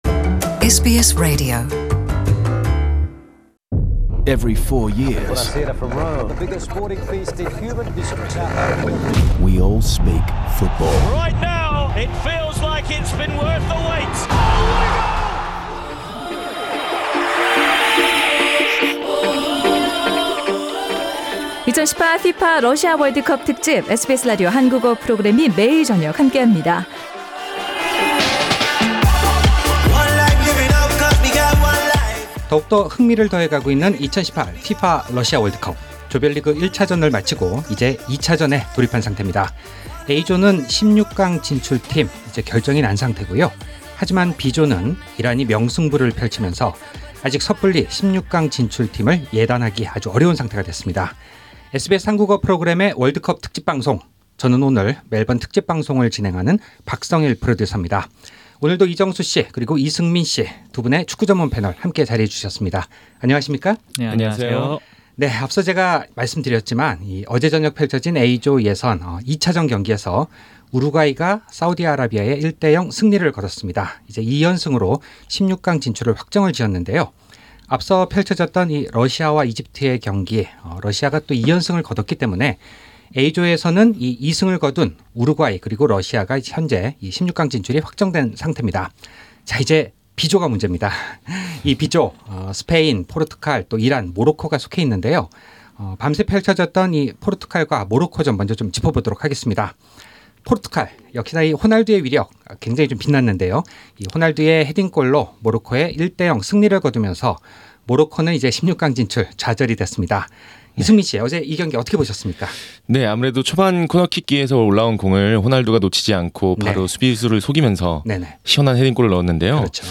The World Cup panel of two in Melbourne